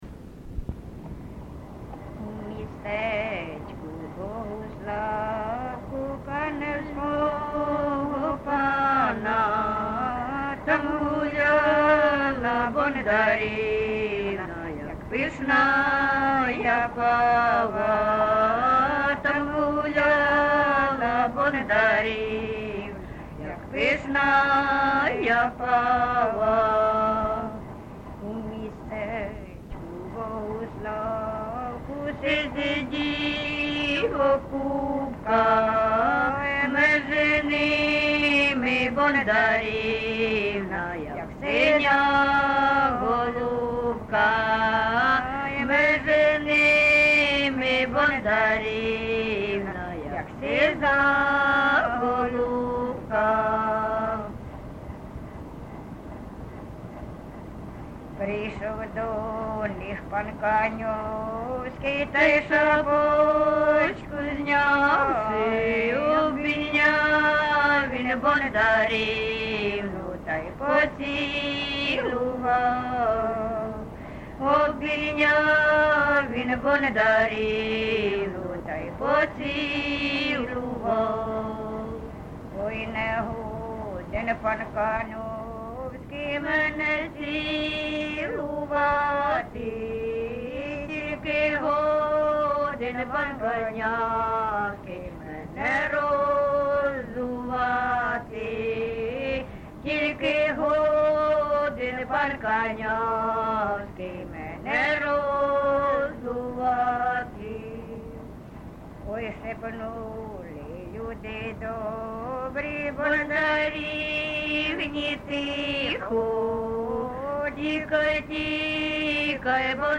ЖанрПісні з особистого та родинного життя, Балади
Місце записус. Харківці, Миргородський (Лохвицький) район, Полтавська обл., Україна, Полтавщина